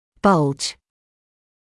[bʌlʤ][балдж]выпуклость; выбухать; пролабировать; выпячиваться